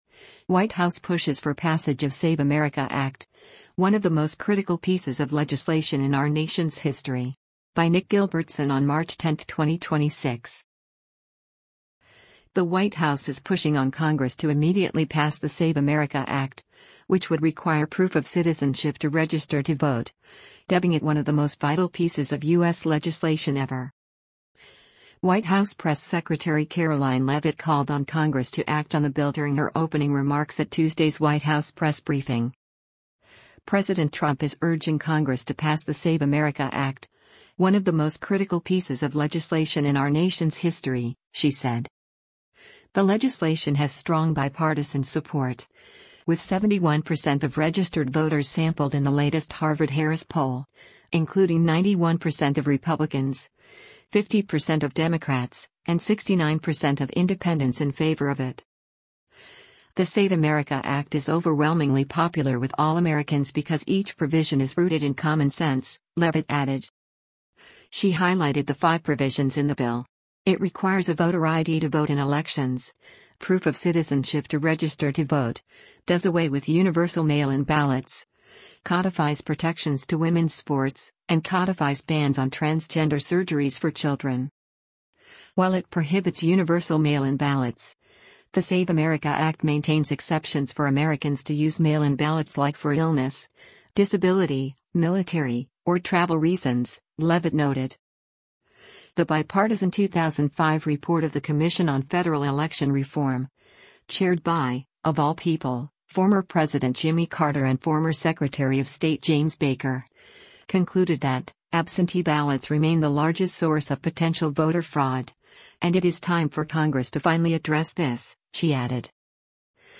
White House press secretary Karoline Leavitt called on Congress to act on the bill during her opening remarks at Tuesday’s White House press briefing.